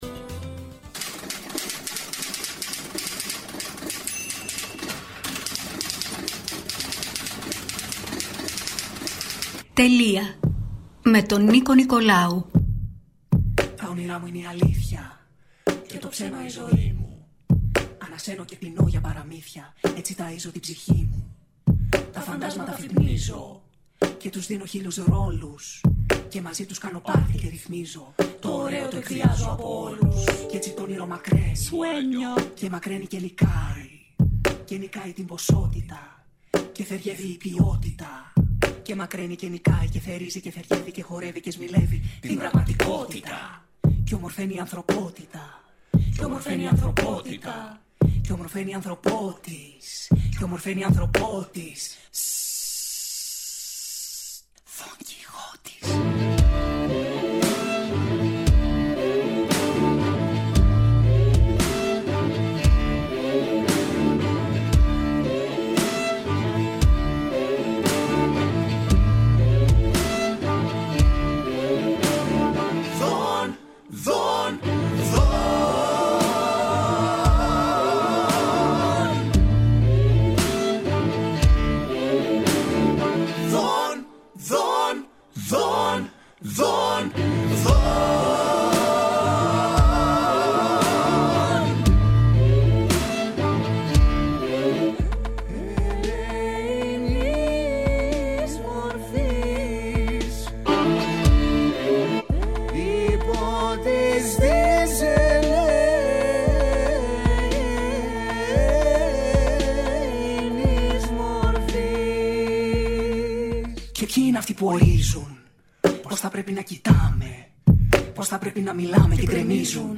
Οι String Demons, λίγο πριν από την σημερινή τους συναυλία στην πλατεία Μικρασιατών, μιλούν στον STYLE 100 για το ξεχωριστό γεγονός(ηχητικό)